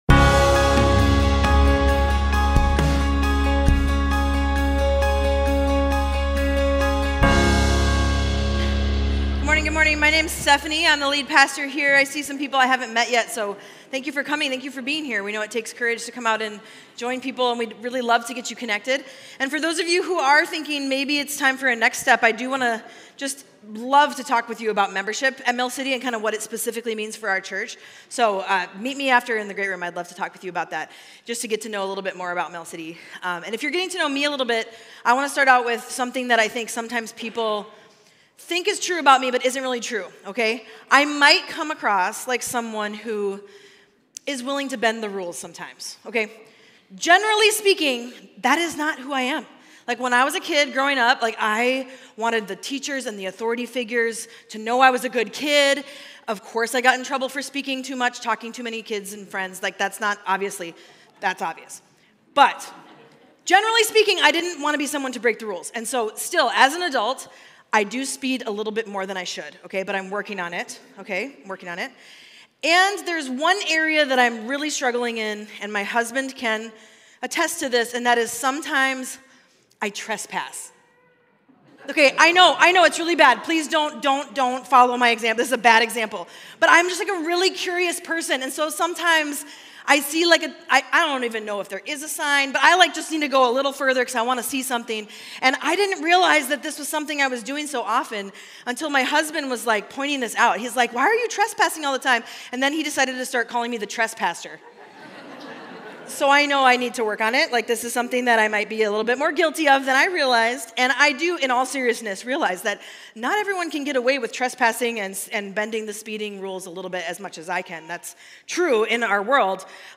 Mill City Church Sermons Authority in the Spirit Aug 05 2024 | 00:39:33 1x 00:00 / 00:39:33 Subscribe Share RSS Feed Share Link Embed